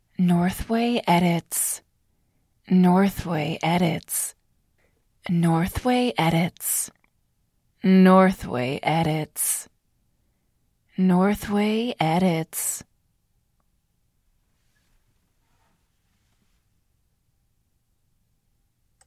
我在说：“Northway编辑” 音频技术麦克风。
Tag: 通话 声音 女孩 性感 美国 声乐 请求女人